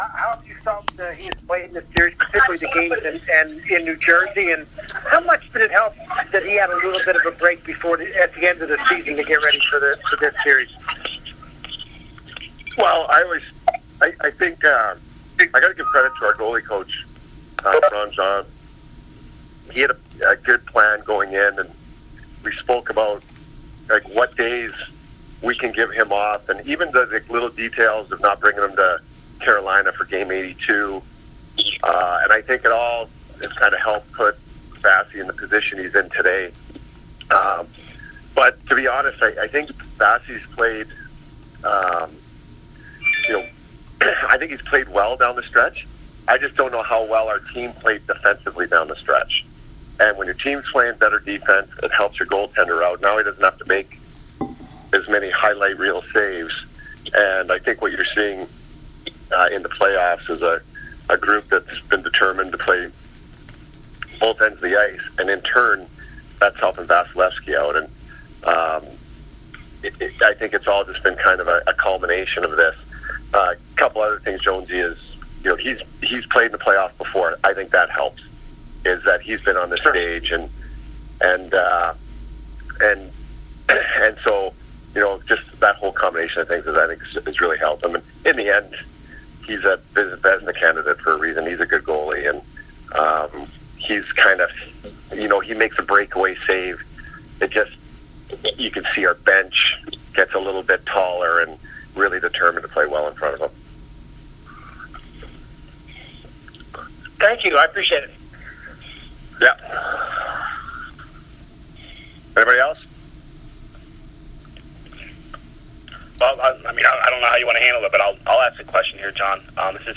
Cooper Conference Call - 4/19
Cooper Conference Call - 4/19 by Tampa Bay Lightning